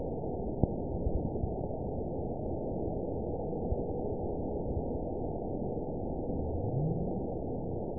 event 920251 date 03/09/24 time 02:07:29 GMT (1 year, 3 months ago) score 9.42 location TSS-AB05 detected by nrw target species NRW annotations +NRW Spectrogram: Frequency (kHz) vs. Time (s) audio not available .wav